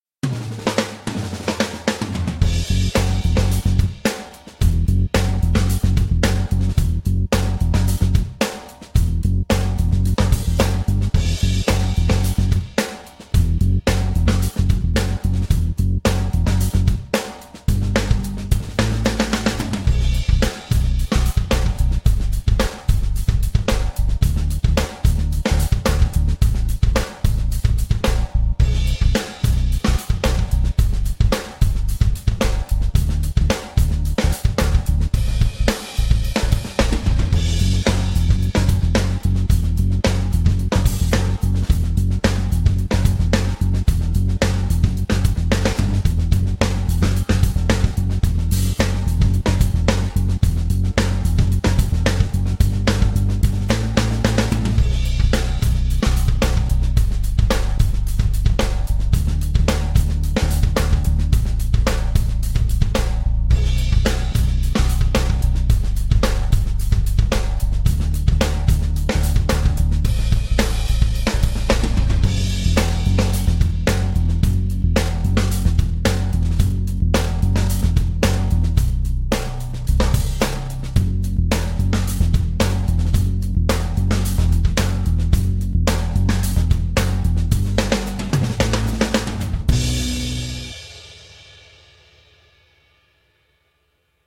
СТИЛЬ: АЛЬТЕРНАТИВА И ХЕВИ-МЕТАЛ
Чтобы сделать занятие более увлекательным, я записал так называемый "симулятор группы" — специальный трек, в котором только ударные и бас, чтобы вы могли под него тренироваться:
Здесь приведены дорожки аккомпанемента в трёх разных темпах: 100, 110 и 120 ударов в минуту:
110 BPM
metal-song-minus-one-track-110bpm.mp3